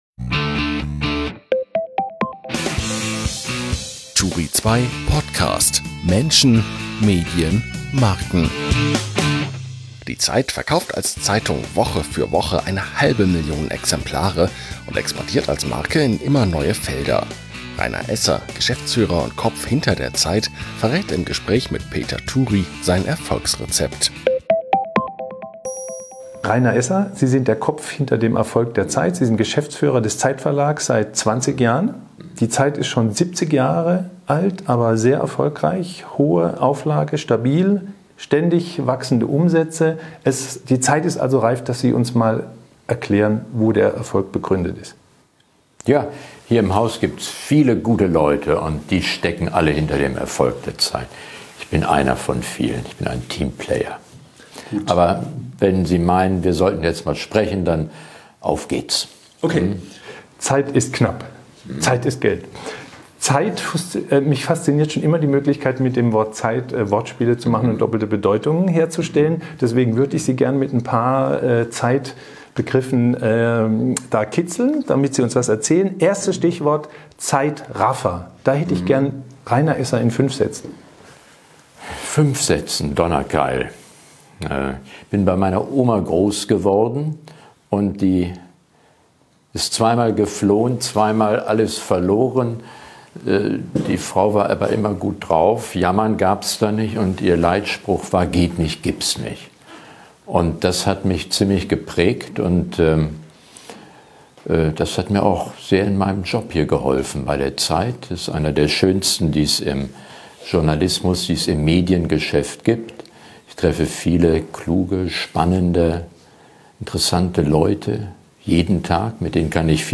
Im turi2 podcast erzählen Medien-, Marken- und Meinungsmacher*innen, wie sie die Herausforderungen der Digitalisierung angehen und was sie persönlich antreibt.